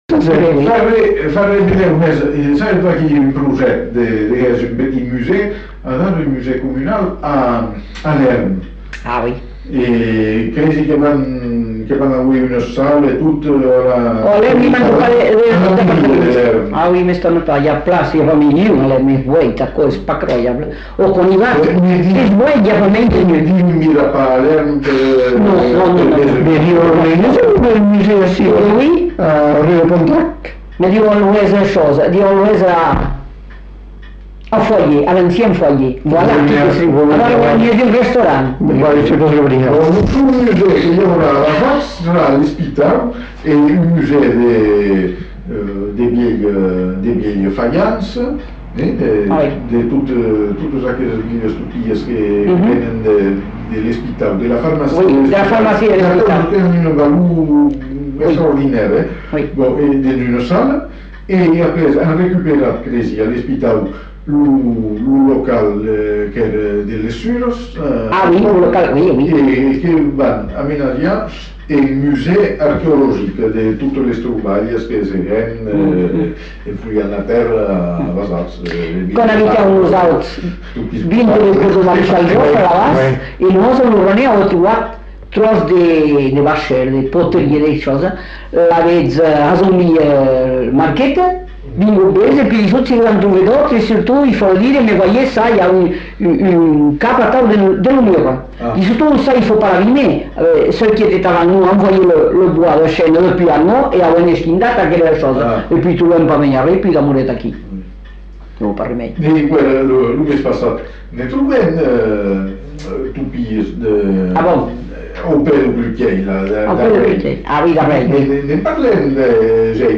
Discussion à bâtons rompus
Lieu : Bazas
Genre : parole
[enquêtes sonores] Discussion avec collecte de vocabulaire occitan